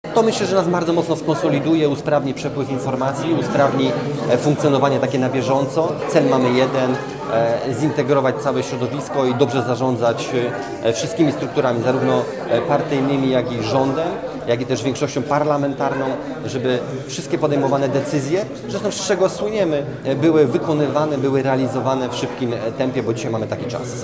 Prezes PiS wraca do rządu aby koordynować jego pracę. Minister Waldemar Buda powiedział Radiu Łódź, że jego obecność ma przyspieszyć procedowanie ustaw i podejmowanie decyzji zarówno w rządzie jak i w parlamencie.